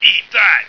flak_m/sounds/male1/int/M1eatthat.ogg at d2951cfe0d58603f9d9882e37cb0743b81605df2